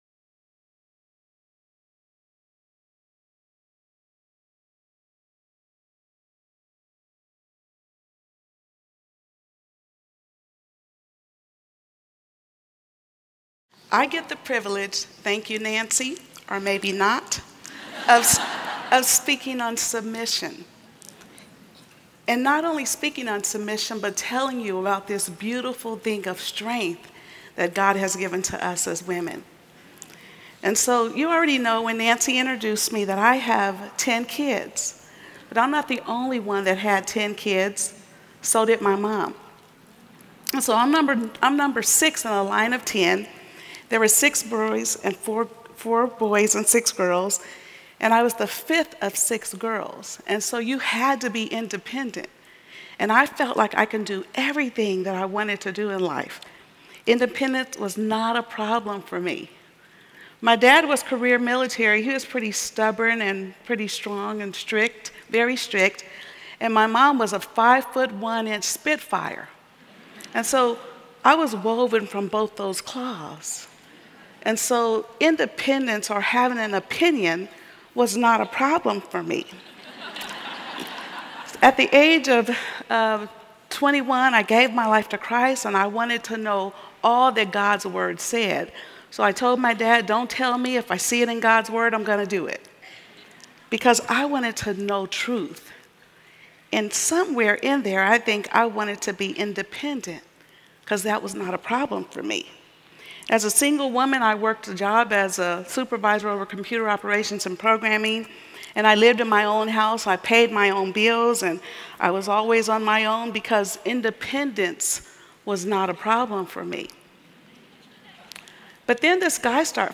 Message 12: An Unexpected Blessing | Revive '17 | Events | Revive Our Hearts